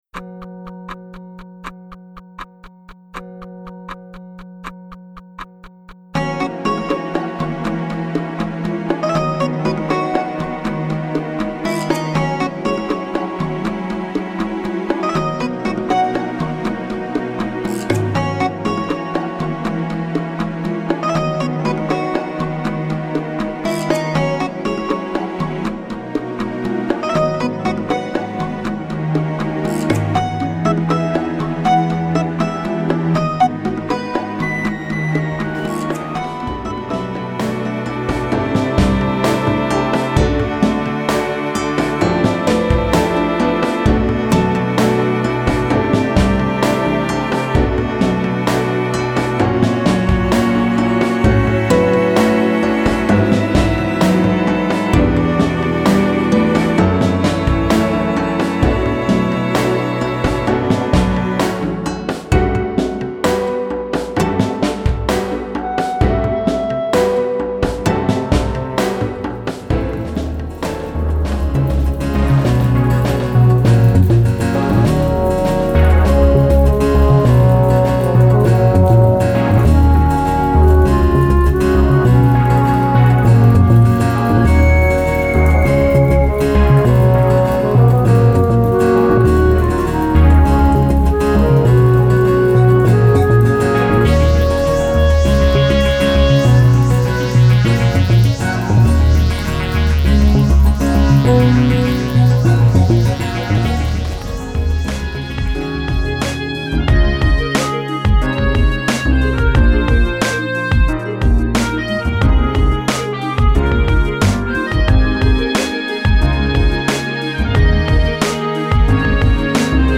■なんとなく素朴なオリジナルインストCD
全曲クロスフェード